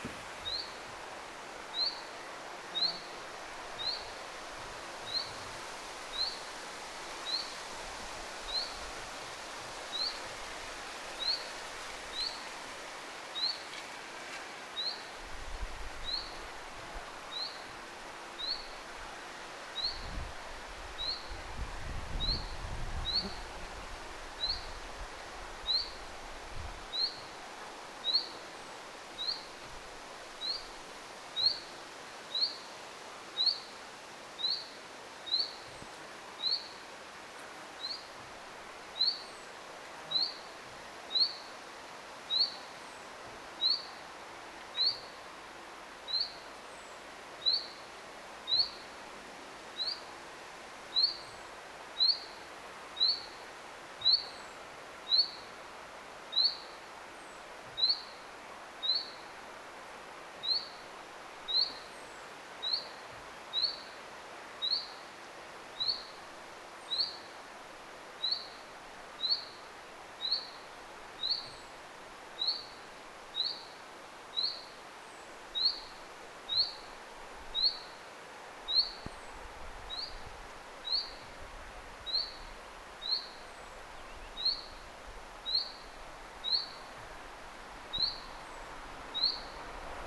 ilmeisesti_hyit-tiltaltin_huomioaantelya.mp3